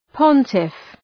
Προφορά
{‘pɒntıf}